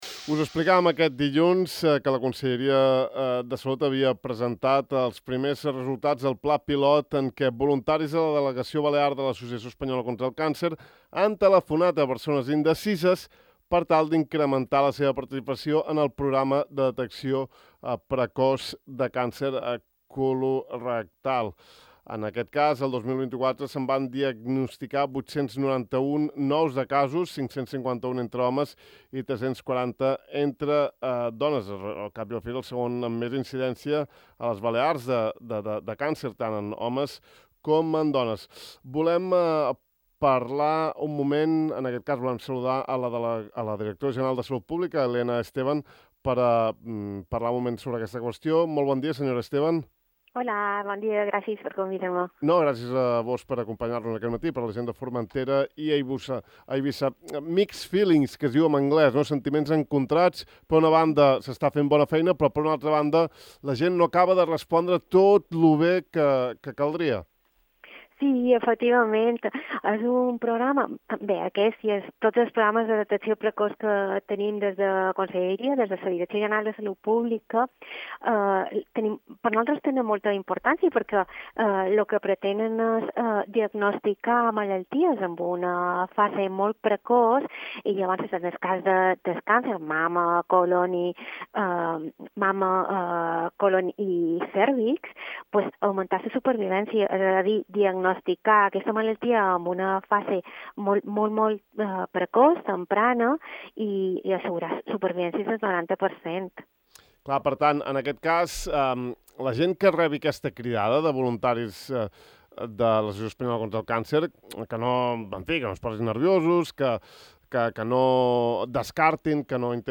En parlem amb la Directora General de Salut Pública del Govern Balear, Elena Esteban: